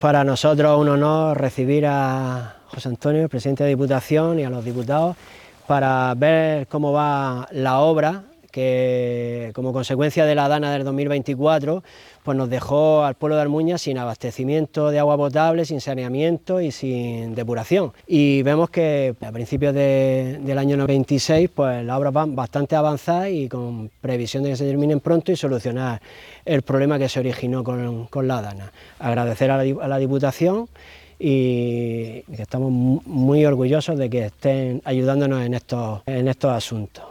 26-01_armuna_alcalde.mp3.mp3